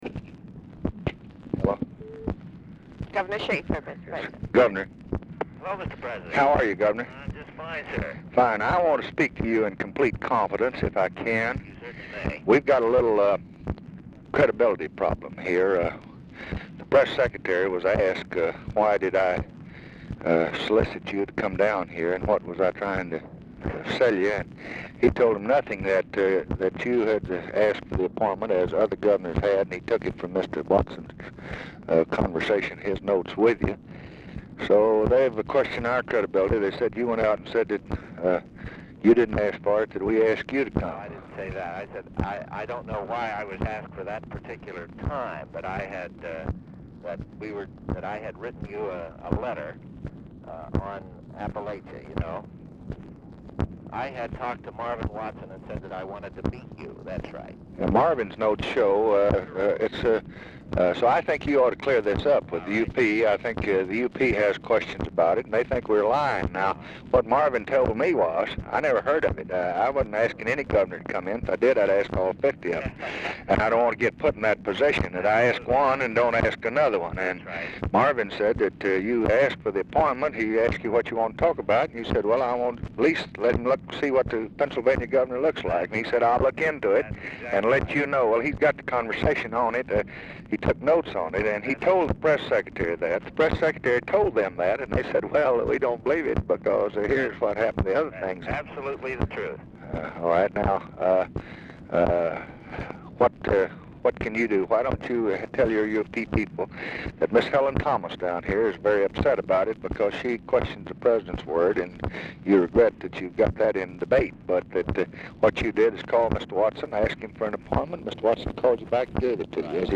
Telephone conversation # 11512, sound recording, LBJ and RAYMOND SHAFER, 2/3/1967, 11:25AM | Discover LBJ
Format Dictation belt
Location Of Speaker 1 Mansion, White House, Washington, DC
Specific Item Type Telephone conversation Subject Press Relations White House Administration